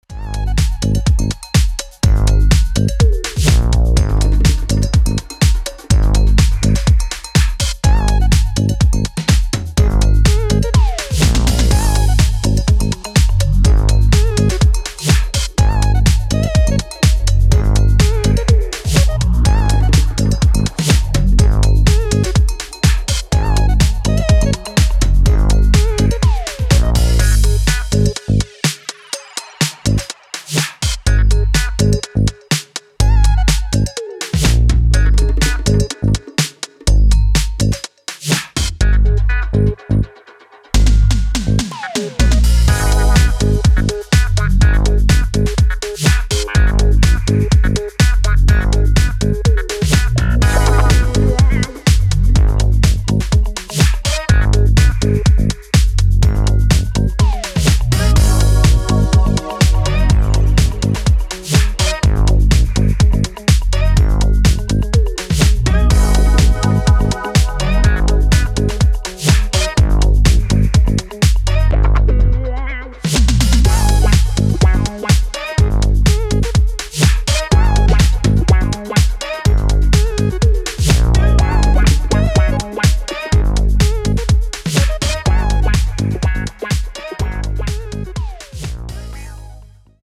こちらもコミカルなスペーシー・シンセのメロディーと歯切れ良いグルーヴが耳に残る